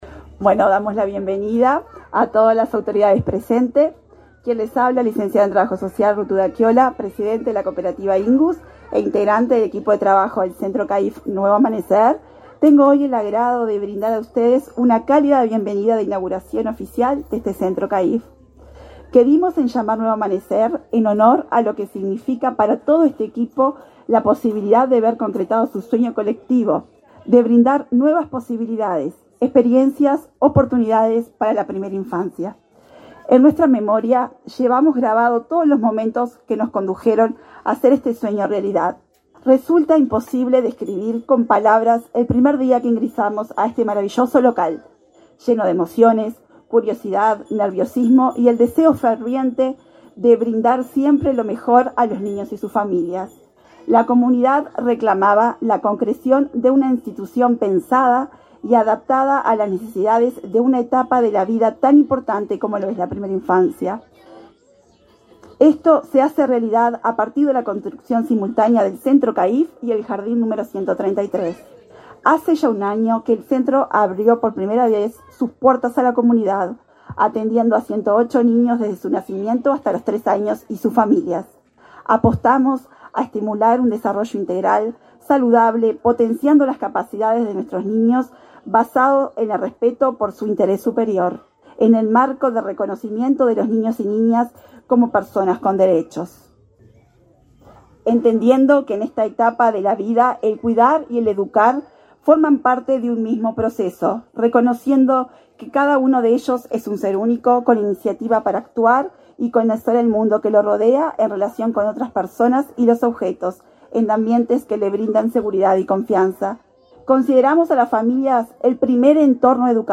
Conferencia de prensa por la inauguración del CAIF Nuevo Amanecer en Salto